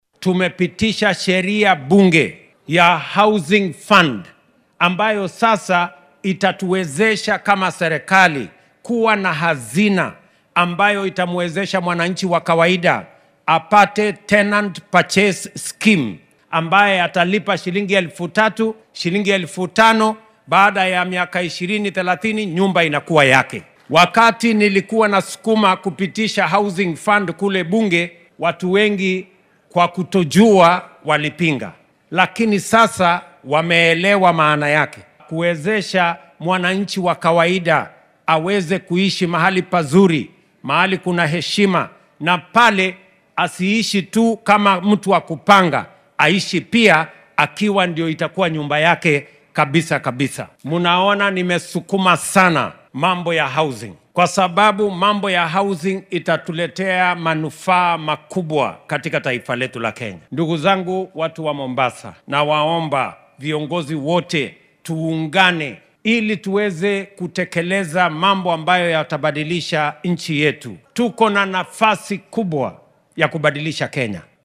Xilli uu ku sugnaa ismaamulka Mombasa ayuu xusay in lacagta dulsaarka ee uu bixinaya qofka nidaamkan adeegsanaya ay sanadkiiba tahay boqolkiiba 3 marka loo barbardhigo qiimaha suuqa kala iibsiga guriyaha oo uu ku sheegay boqolkiiba 18.